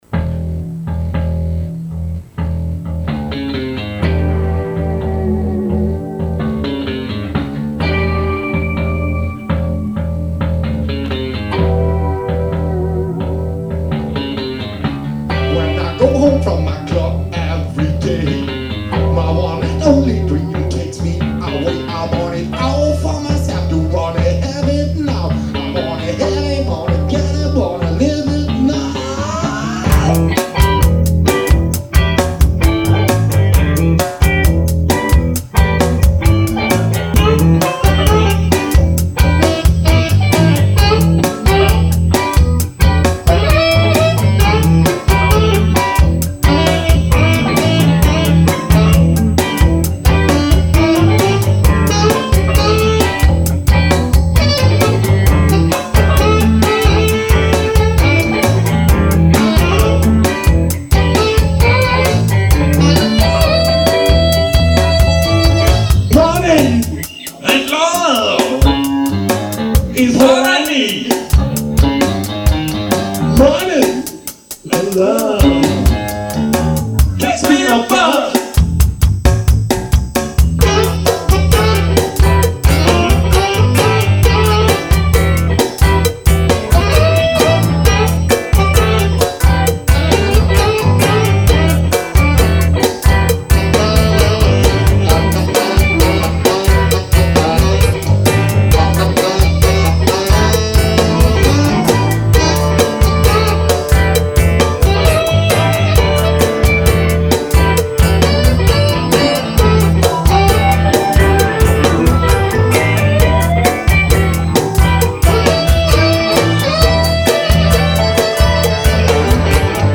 Ein Stück aus der ersten Zeit im Stelle Studio, live gespielt und ursprünglich nur mit zwei Micros aufgenommen. Weil es sehr lebendig rüberkommt und die Aufnahmequalität ziemlich gut war, hat es sich angeboten für etwas Auffrischung mit den Möglichkeiten, die uns heute zur Verfügung stehen.
Lead Guitar, Lead Vocal
Rythm Guitar, add.Shouts
Alto Sax
Bass Guitar, add.Shouts, Programming, Remix